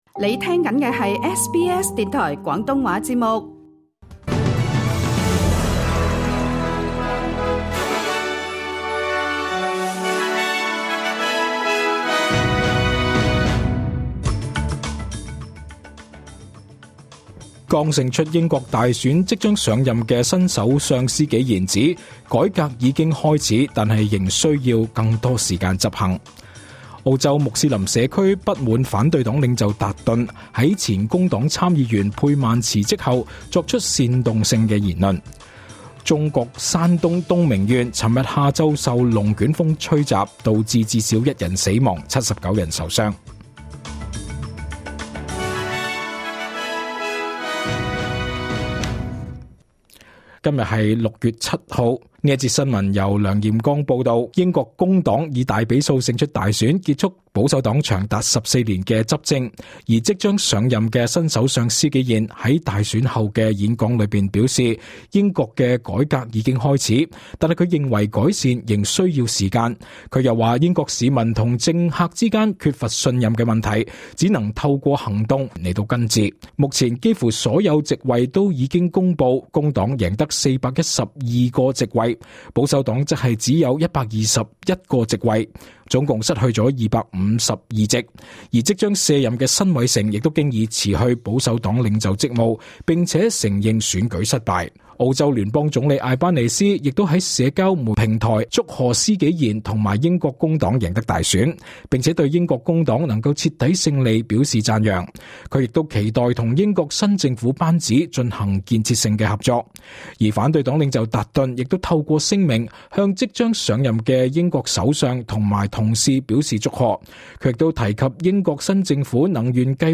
2024 年 7 月 6 日 SBS 廣東話節目詳盡早晨新聞報道。